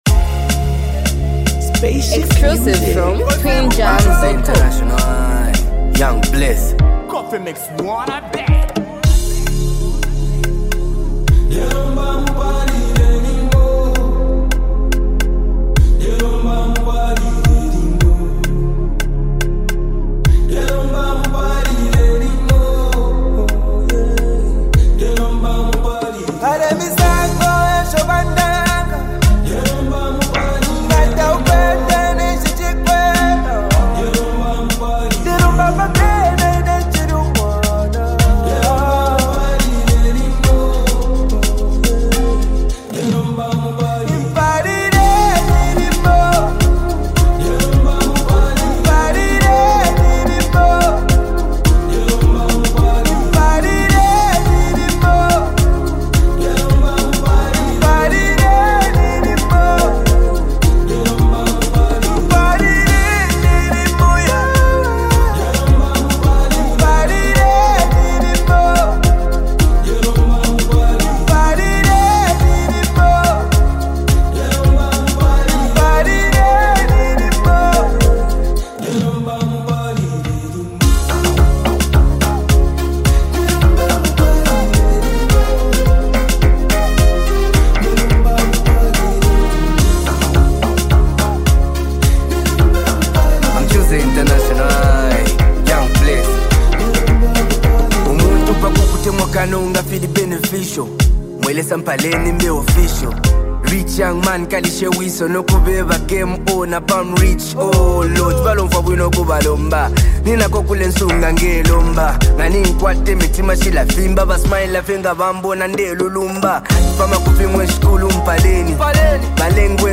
heartfelt and emotionally expressive song
complements the track with a smooth and soulful contribution